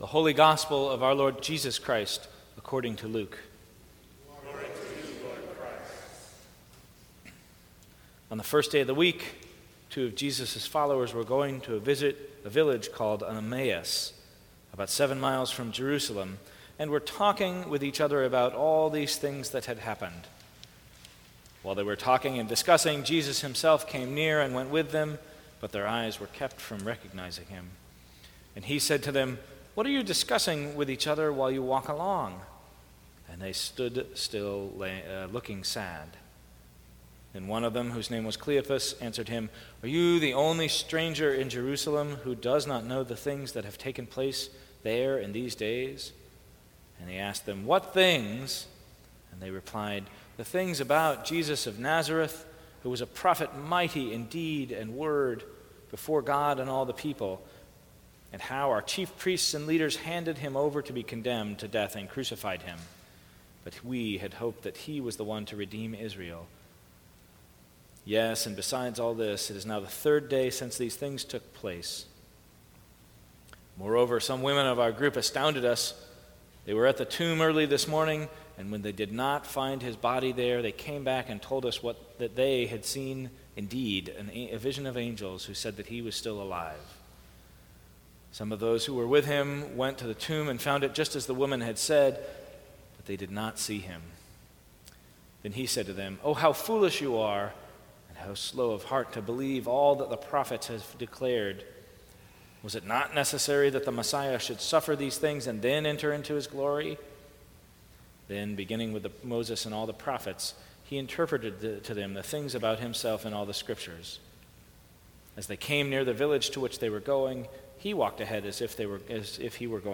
Sermons
St. Cross Episcopal Church